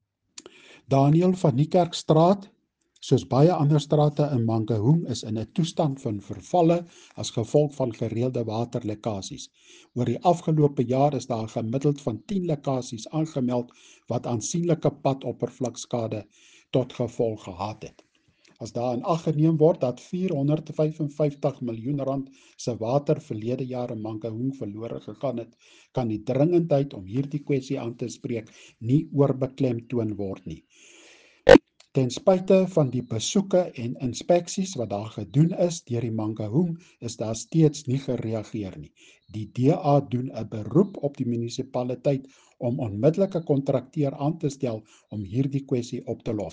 Afrikaans soundbites by Cllr Hennie van Niekerk and